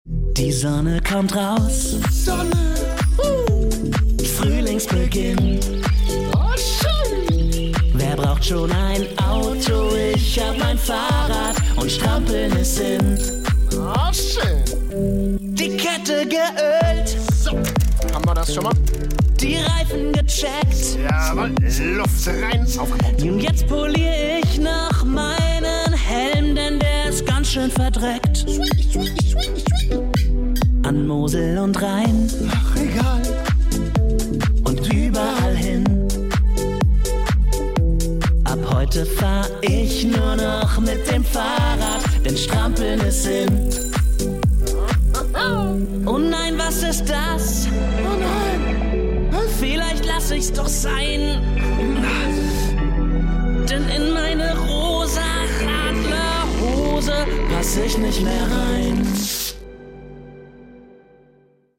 Gagsong zum Radfahren als Video